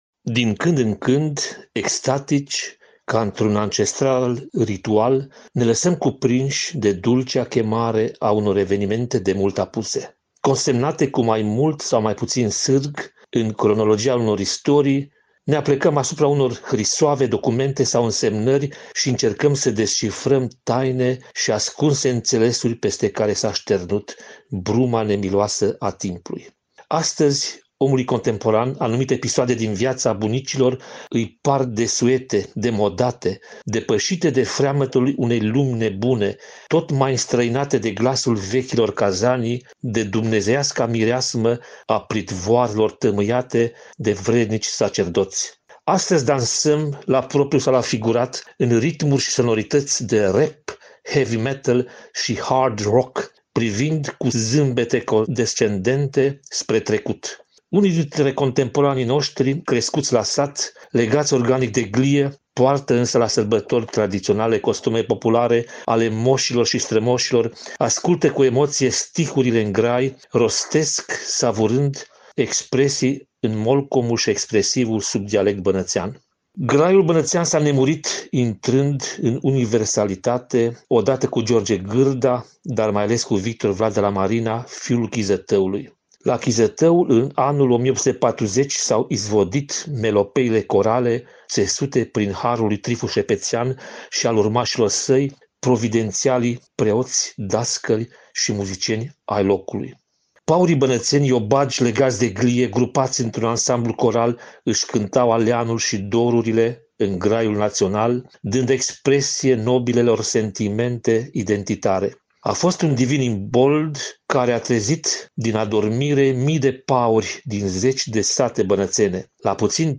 interviu, în exclusivitate, pentru Radio Timișoara